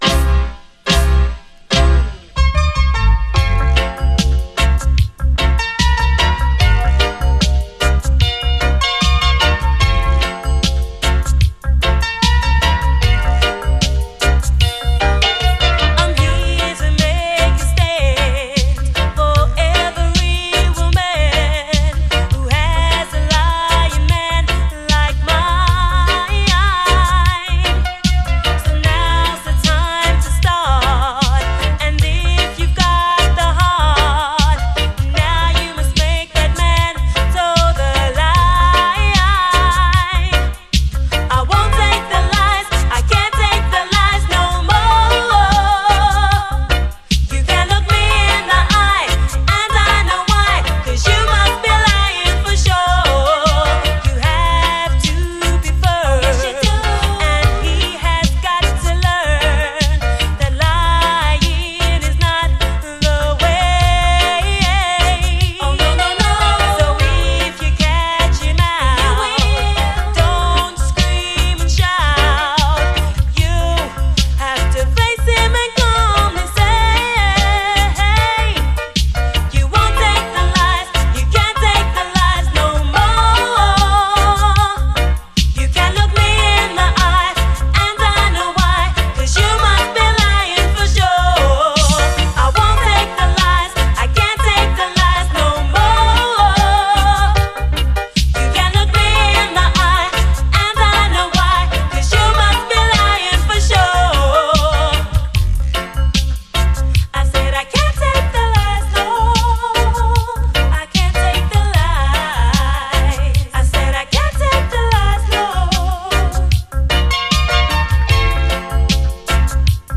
REGGAE
意外と熱くエモーショナルな一曲！